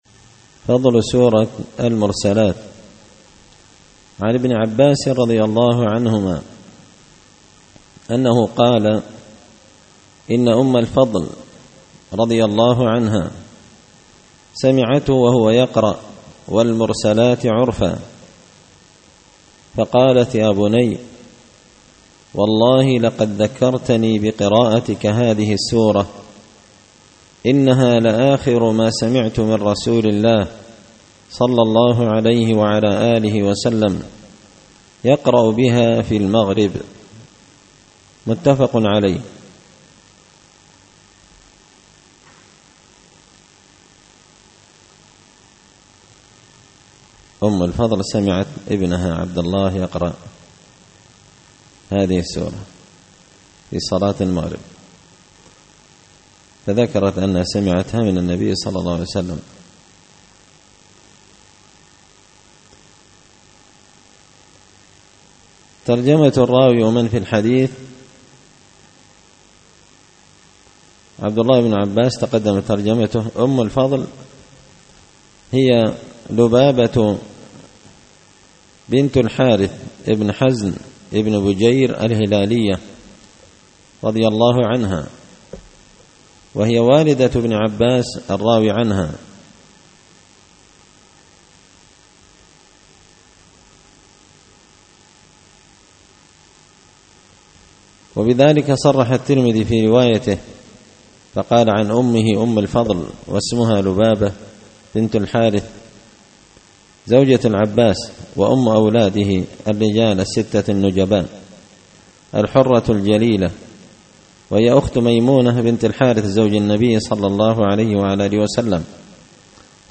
الأحاديث الحسان فيما صح من فضائل سور القرآن ـ الدرس الثاني والأربعون
دار الحديث بمسجد الفرقان ـ قشن ـ المهرة ـ اليمن